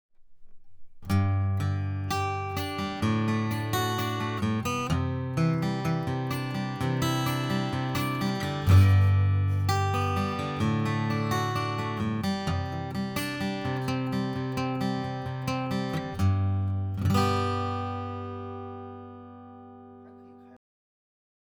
aguitar2-CK63_1.wav